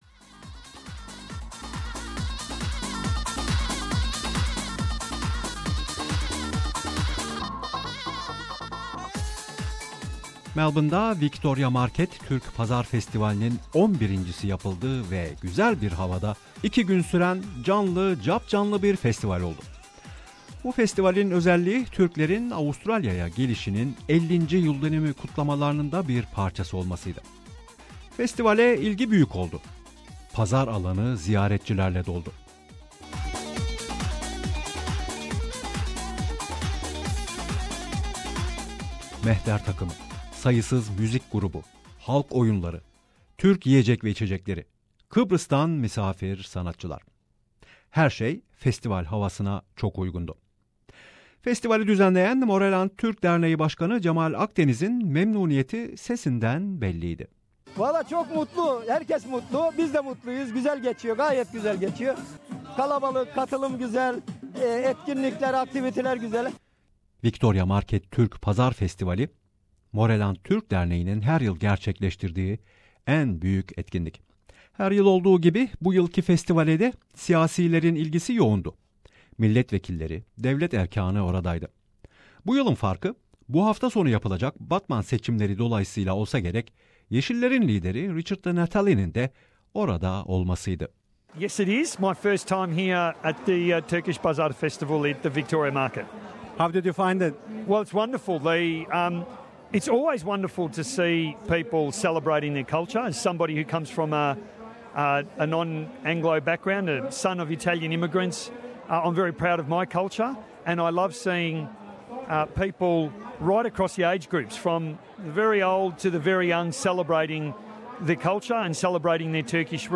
11. Victoria Market Türk Festivali'nden söyleşiler